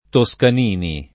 [ to S kan & ni ]